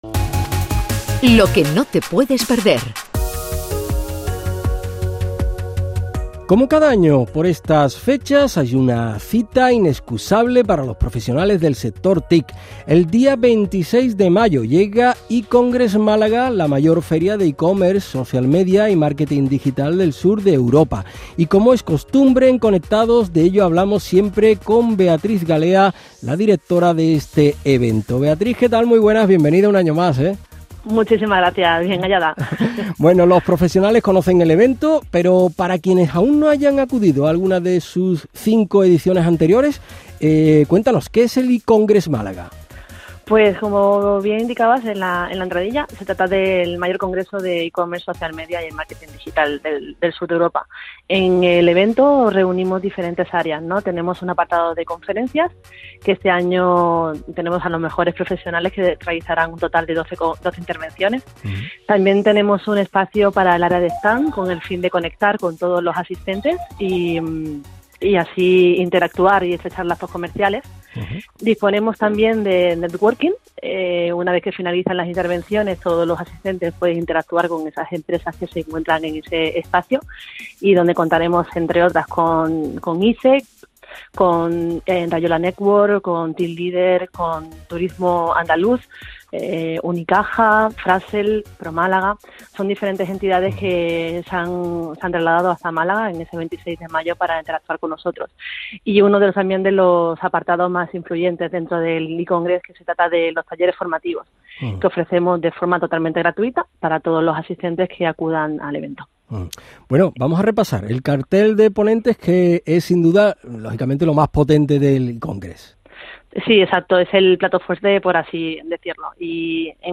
Entrevista 2018 para Canal Sur
Os dejamos una entrevista de 2018 para el Programa Conectados de Canal Sur Radio